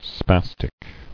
[spas·tic]